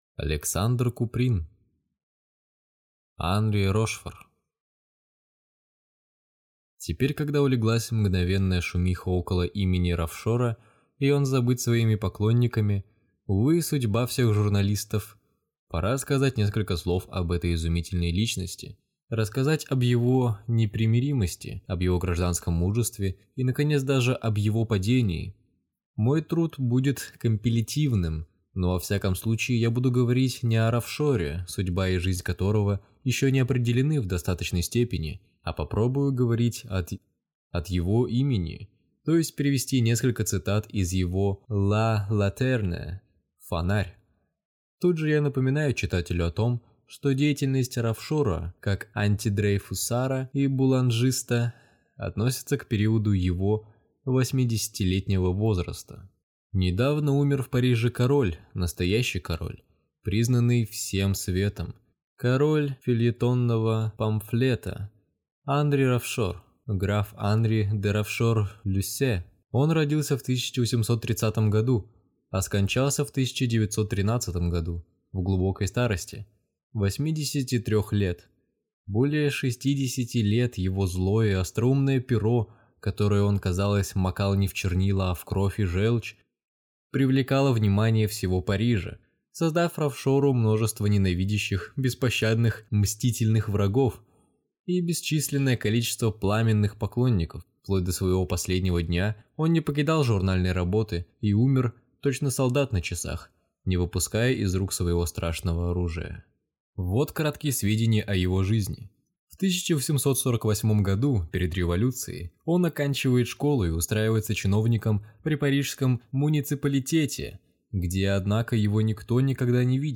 Аудиокнига Анри Рошфор | Библиотека аудиокниг